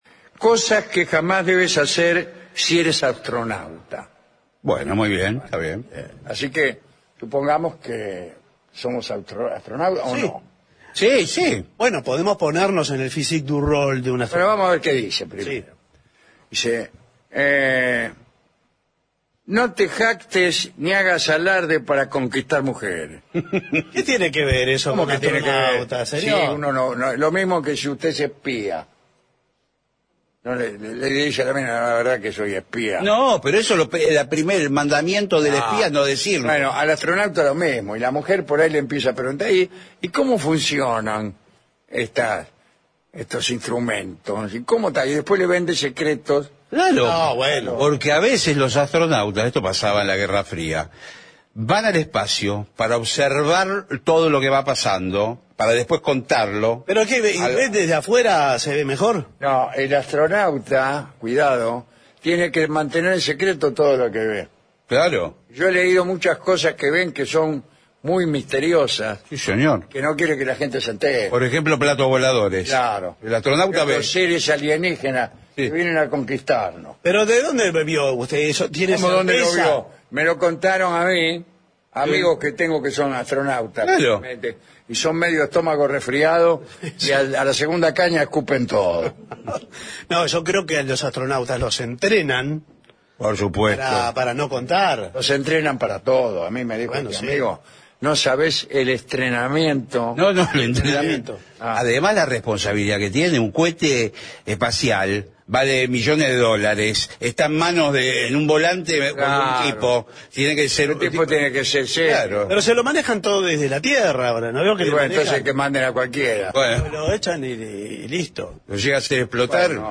Un clásico de la radio rioplatense con la conducción de Alejandro Dolina